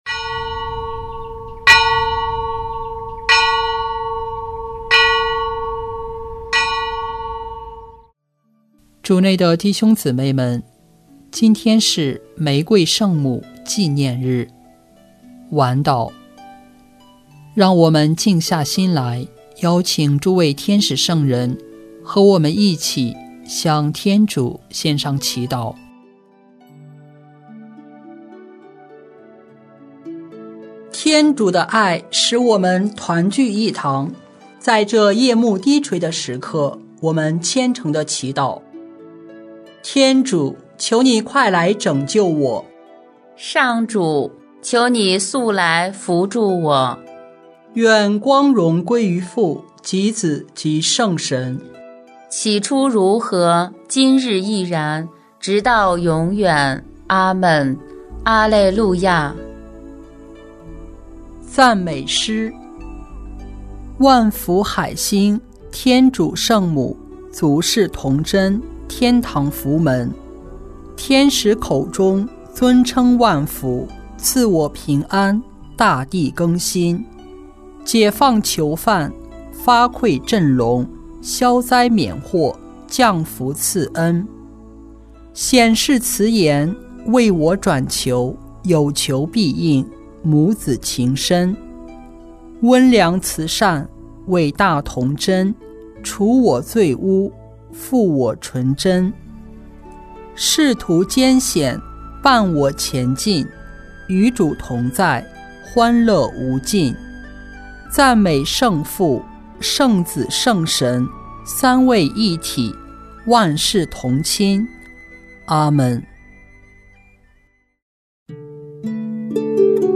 【每日礼赞】|10月7日玫瑰圣母纪念日晚祷